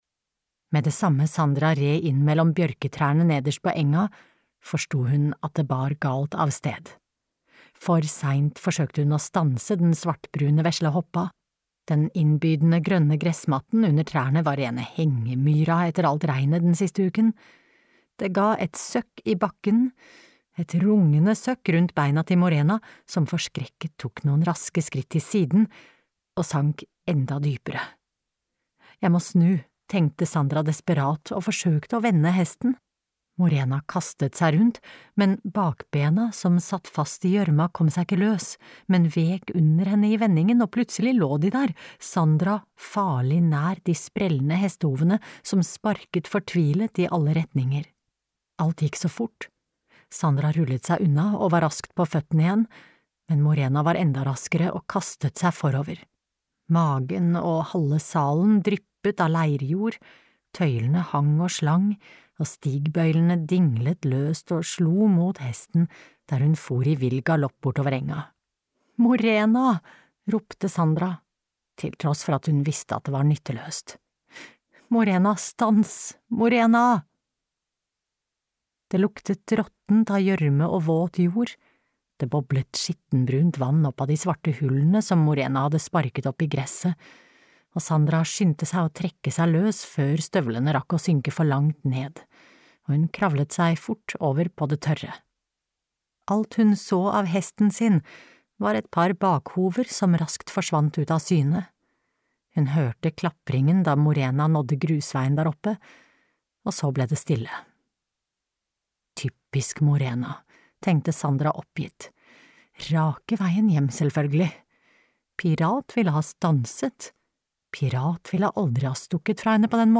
Sandra på norrløvsta (lydbok) av Lisbeth Pahnke